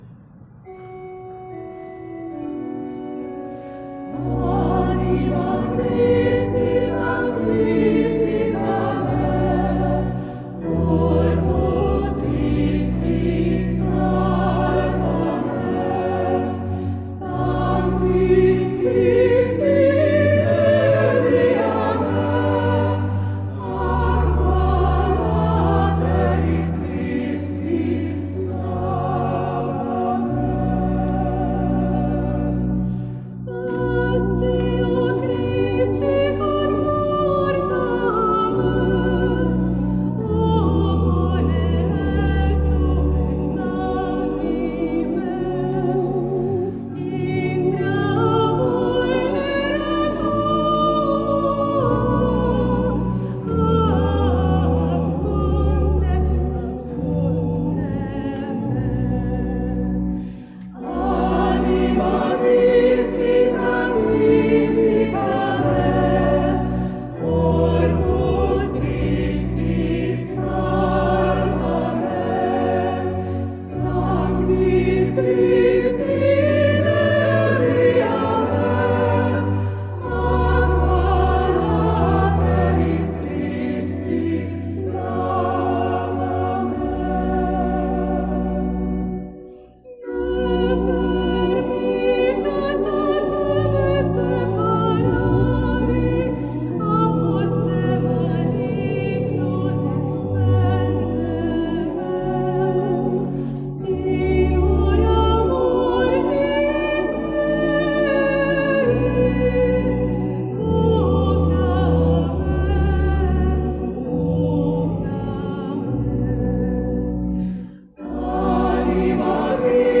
Anima Christi, szólót énekel
Egyházzene